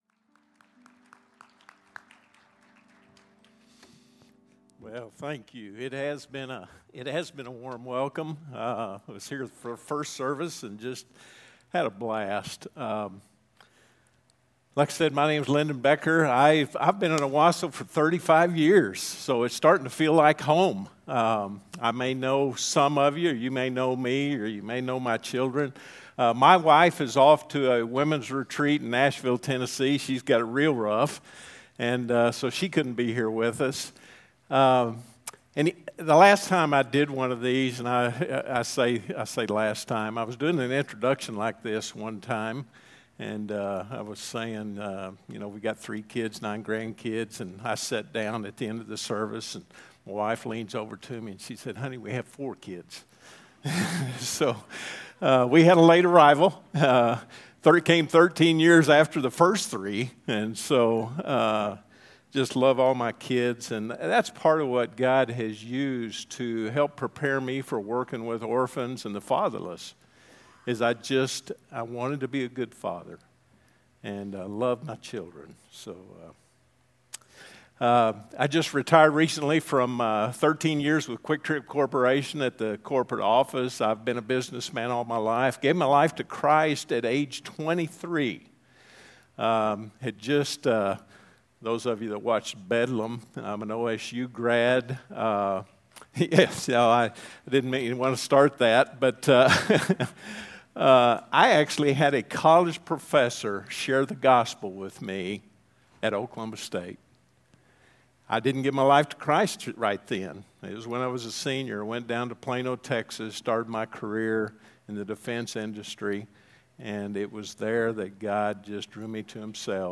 A message from the series "Asking for a Friend."